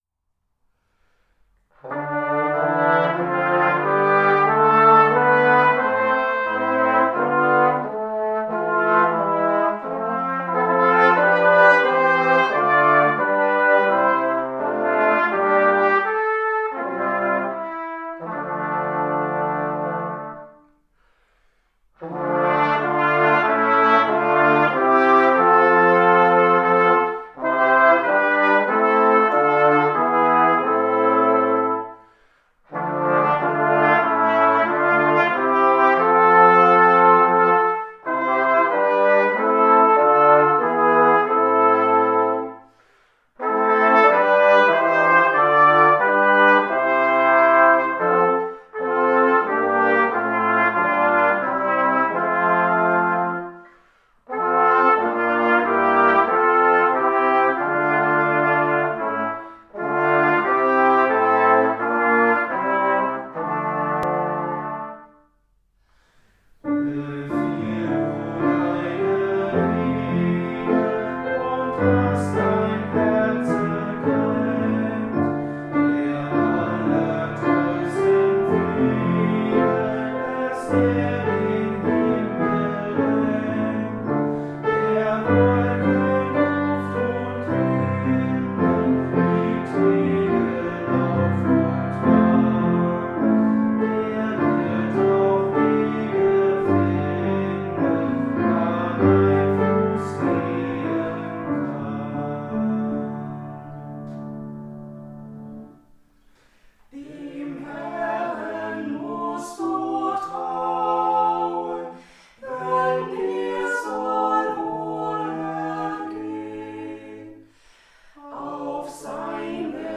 Täglich veröffentlicht die Landeskirche Anhalts als Video oder Audio ein geistliches Musikstück mit Musikerinnen und Musikern aus Anhalt sowie Informationen dazu und der jeweiligen Tageslosung mit Lehrtext.